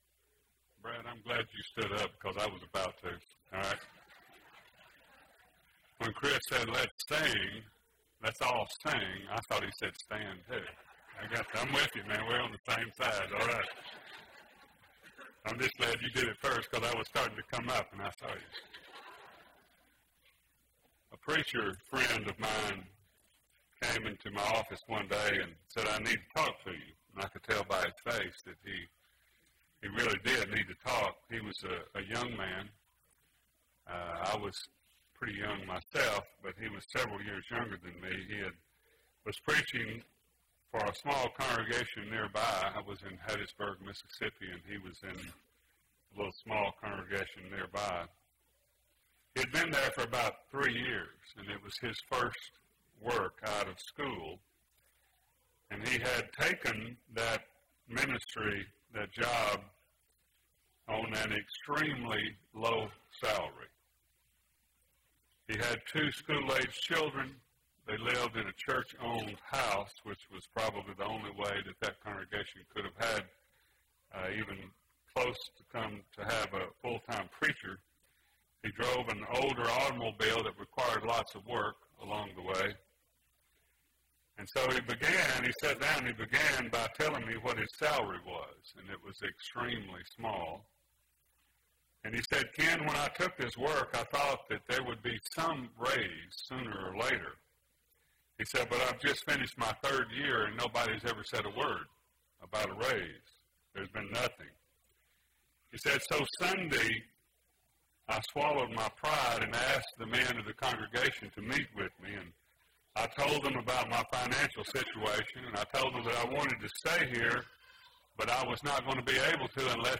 Sunday PM Sermon